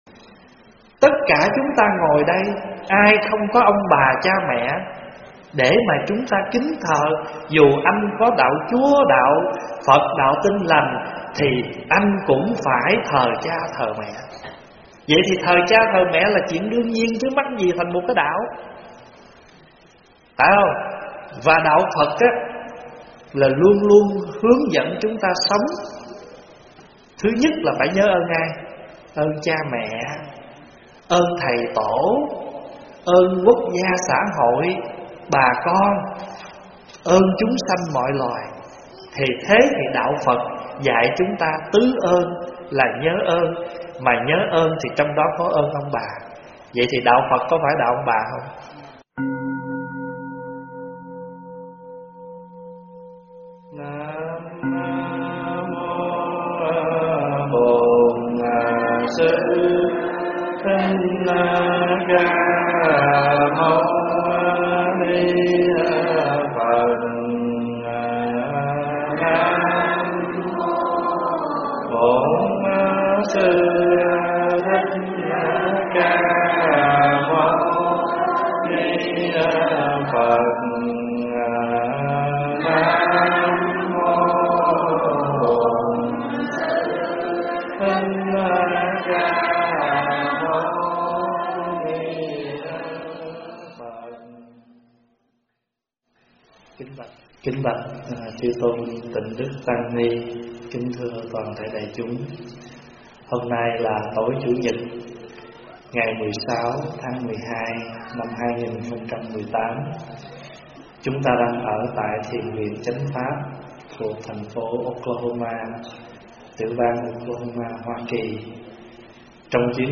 Nghe mp3 vấn đáp Đạo Phật - Đạo Ông Bà